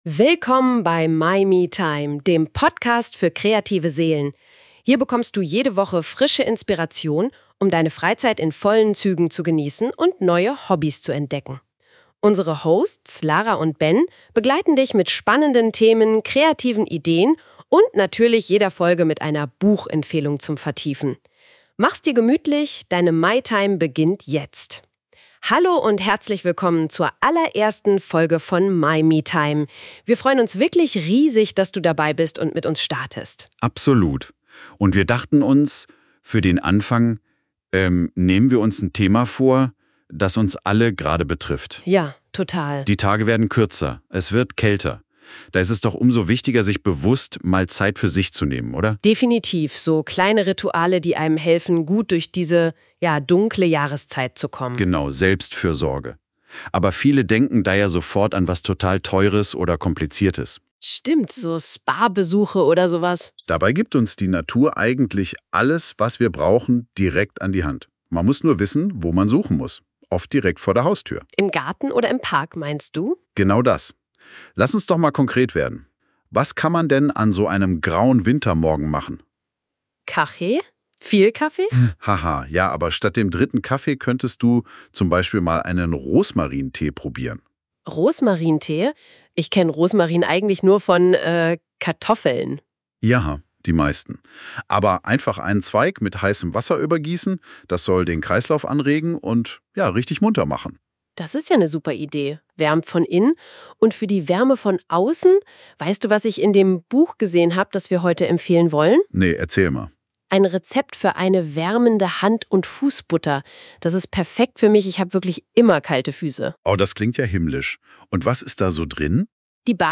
schaffen durch persönliche Anekdoten und humorvolle Dialoge eine